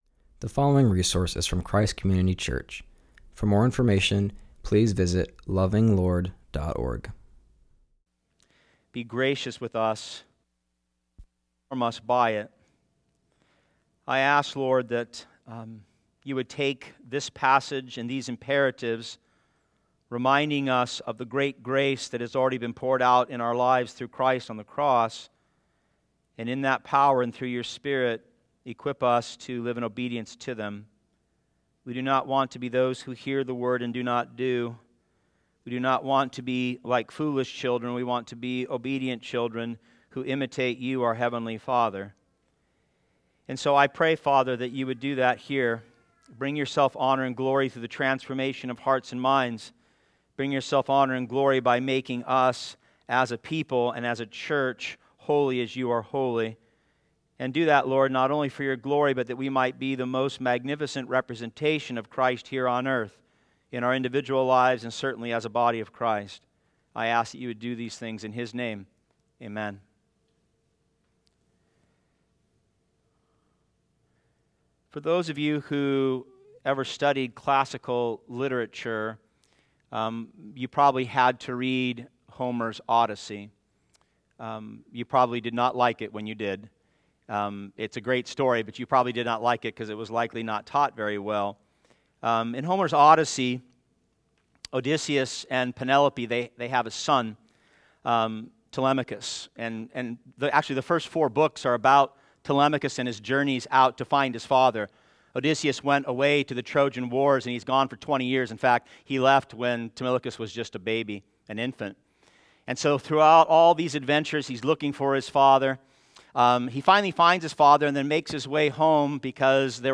preaches from Ephesians 4:29-5:2.